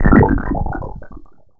poison.wav